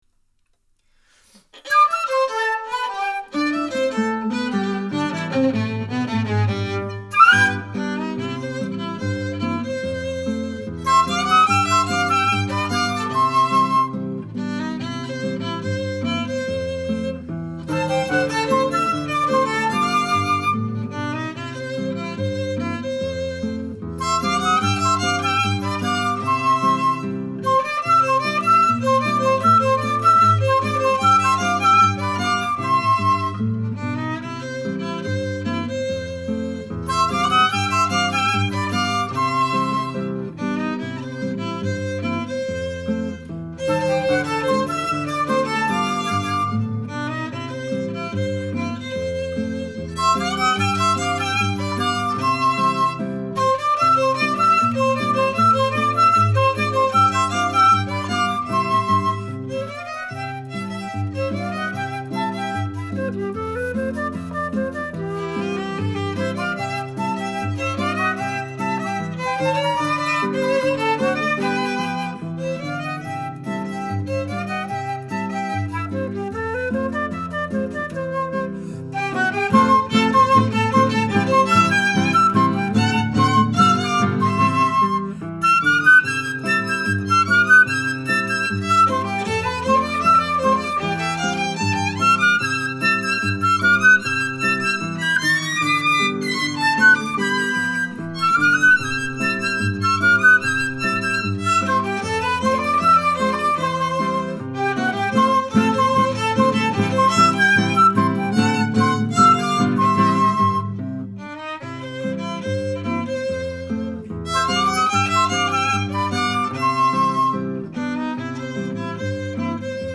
registrato dal vivo: Verona, dicembre 2012